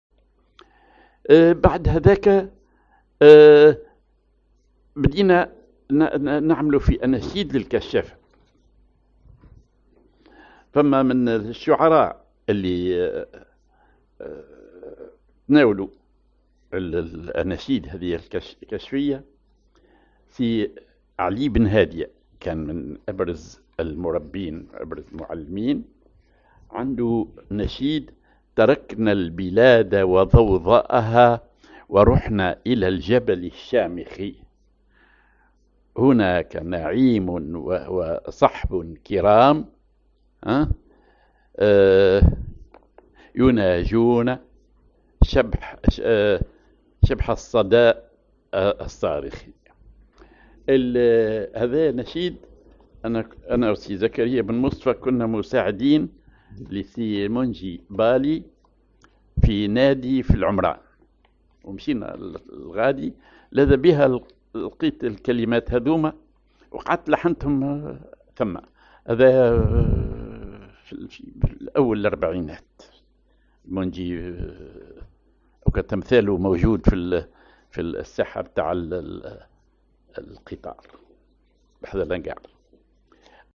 Maqam ar فا كبير
genre نشيد